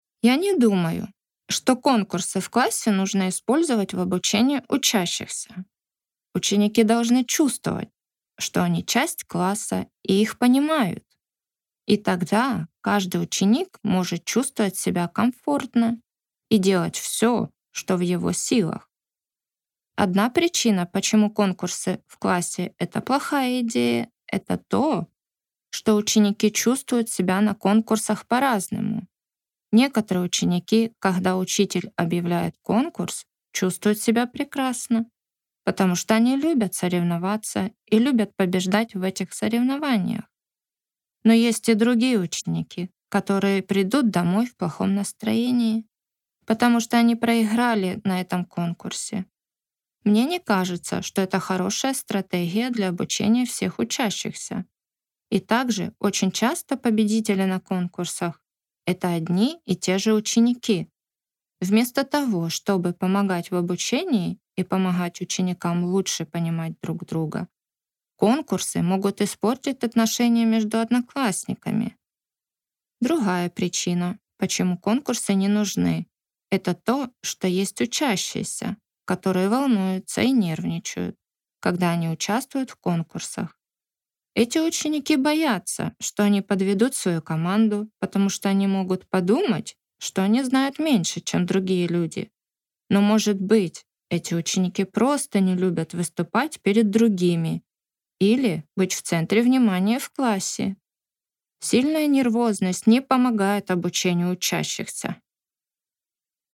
[Note: In the transcript below, ellipses indicate that the speaker paused.]